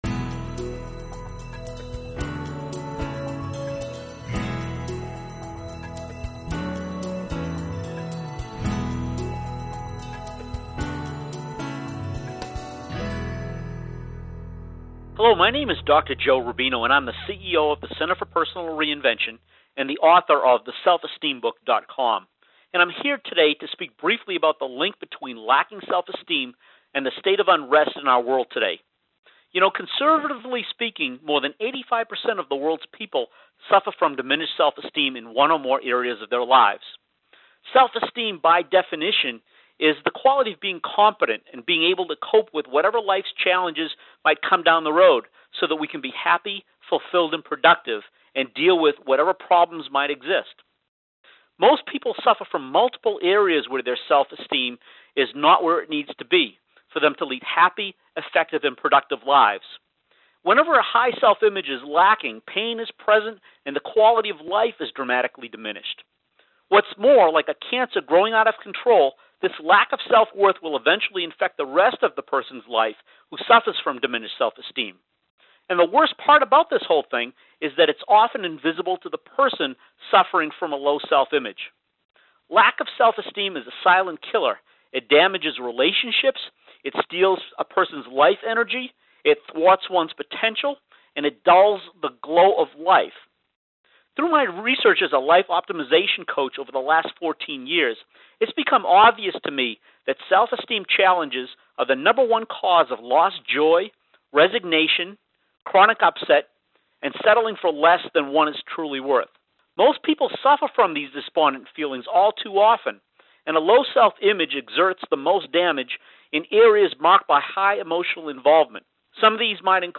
Behind Closed Doors Success Interview